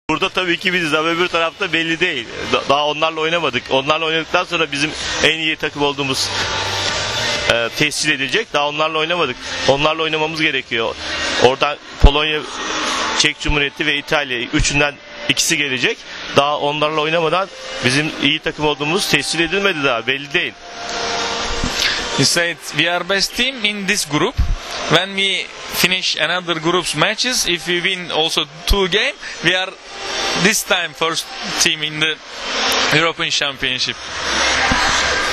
IZJAVA
SA PREVODOM